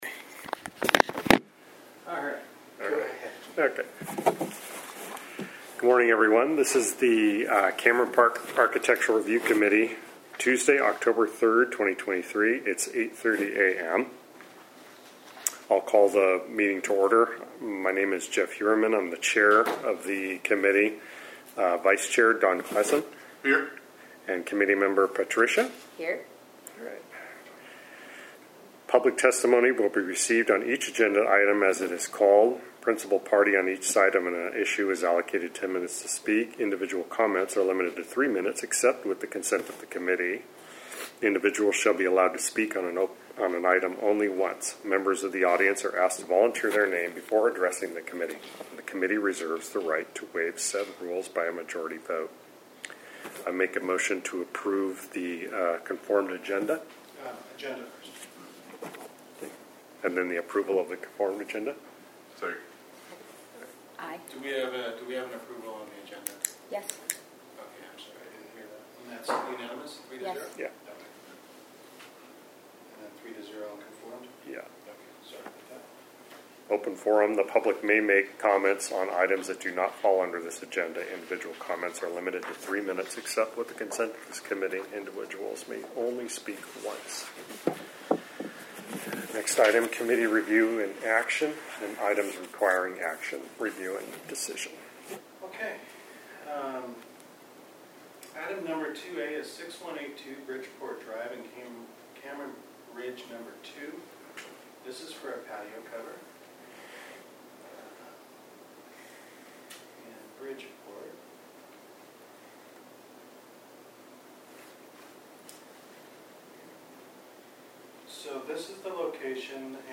Architectural Review Committee Meeting